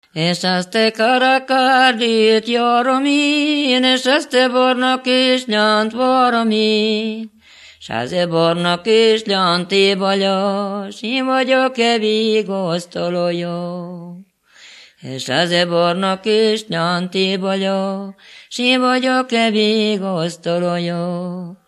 Moldva és Bukovina - Moldva - Klézse
Stílus: 8. Újszerű kisambitusú dallamok
Szótagszám: 9.9.9.9
Kadencia: 4 (3) 2 1